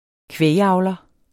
kvægavler substantiv, fælleskøn Bøjning -en, -e, -ne Udtale [ ˈkvεj- ] Betydninger landmand som ejer og opdrætter kvæg til kød- eller mælkeproduktion Se også kvægbruger Oksekød er ikke bare oksekød.